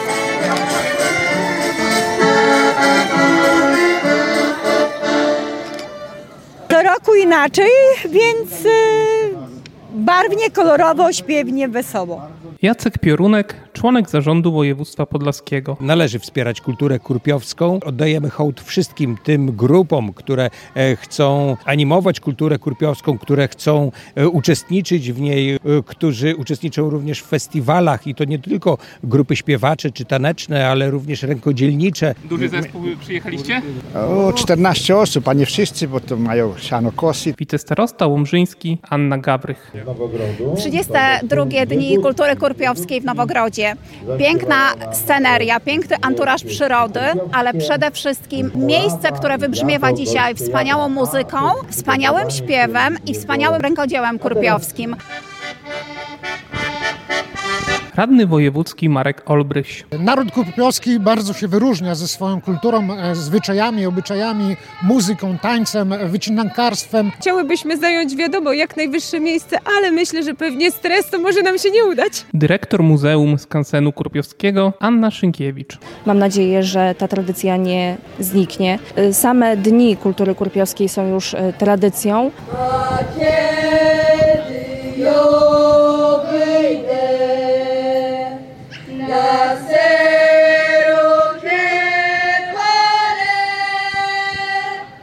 Impreza, jak co roku, odbyła się w amfiteatrze nad Narwią na terenie Muzeum-Skansenu Kurpiowskiego im. Adama Chętnika.
Więcej w naszej relacji: